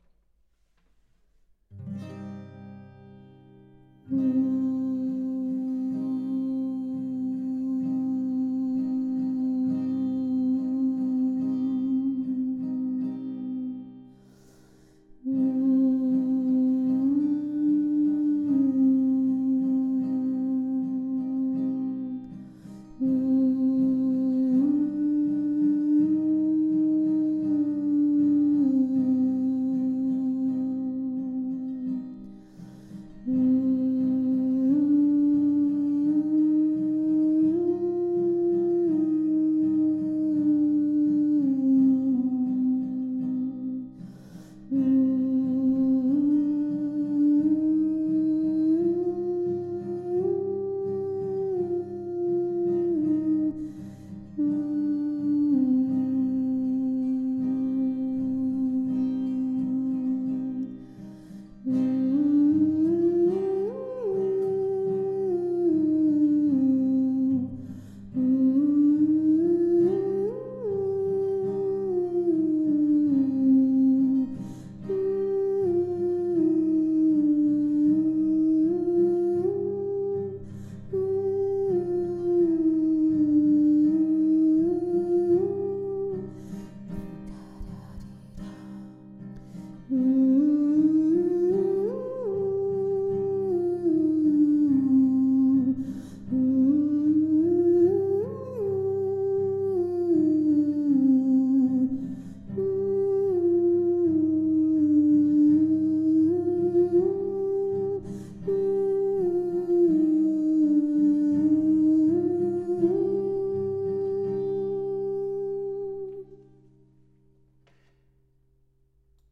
Maienwind - summen
maienwind-summen-melodie.mp3